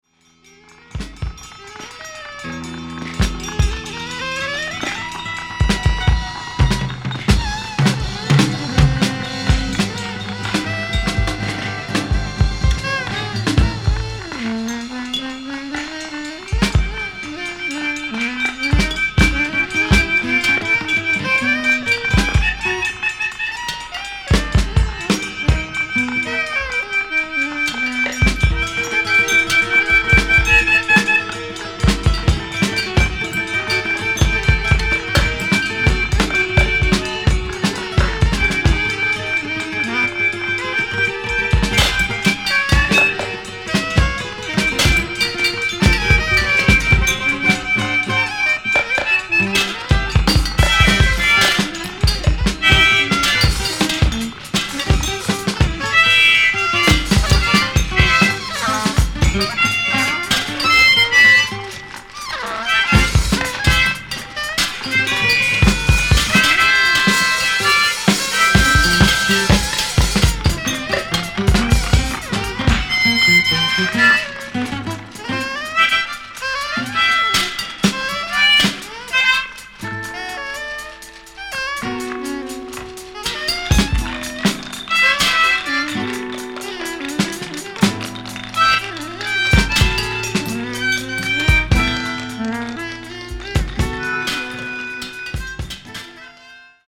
即興　脱線パンク　宅録